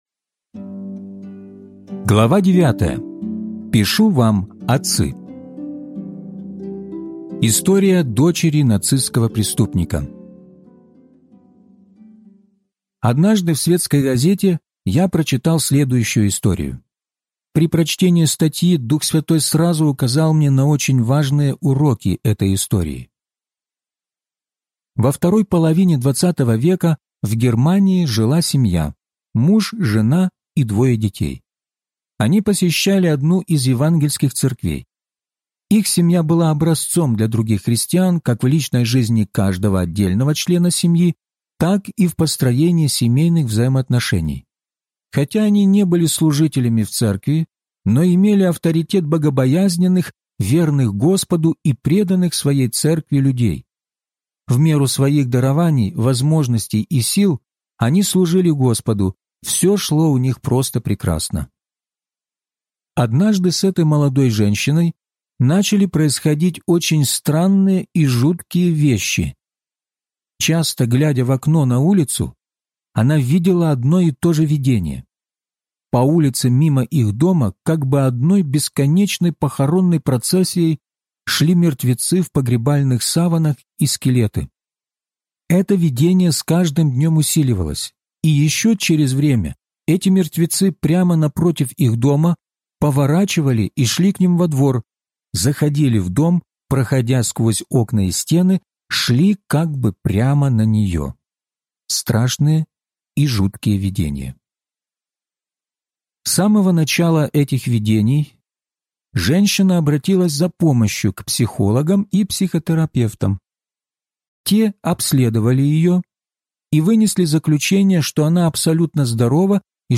Возрастайте! (аудиокнига) - День 27 из 34